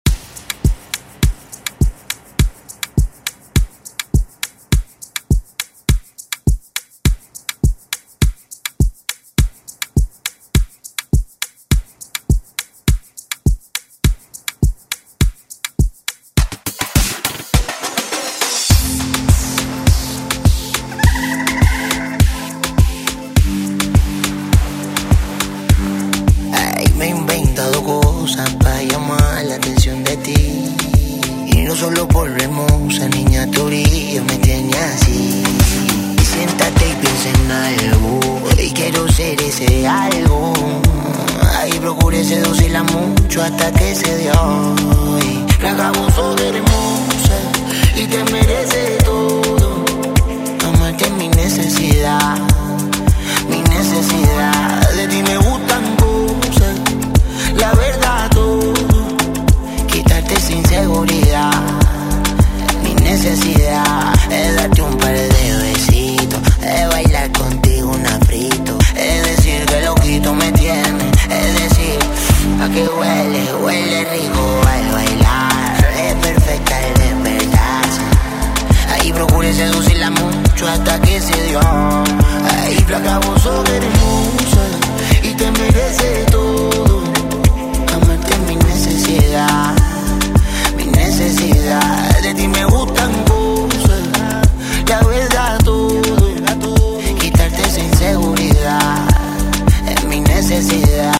Genres: 60's , RE-DRUM
Clean BPM: 130 Time